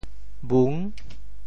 Details of the phonetic ‘bhung7’ in region TeoThew
IPA [bun]